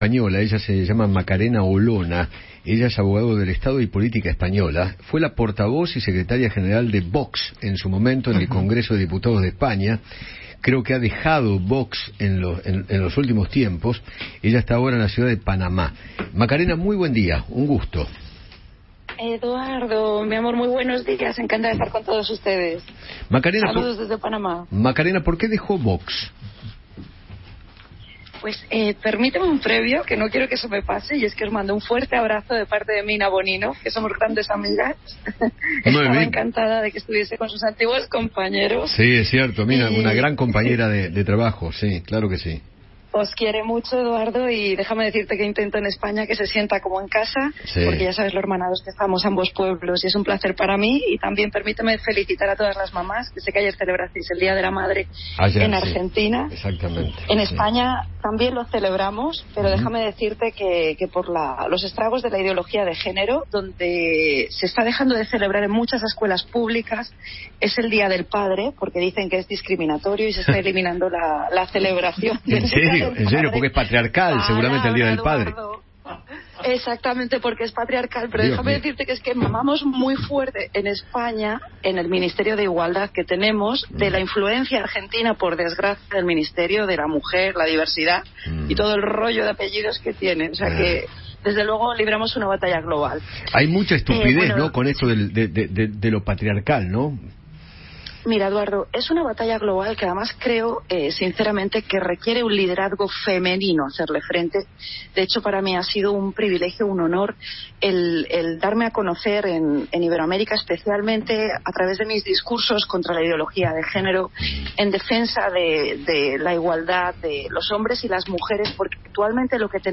Macarena Olona, ex portavoz de Vox y miembro del Parlamento de España, habló con Eduardo Feinmann sobre las razones que la llevaron a dejar el partido político español.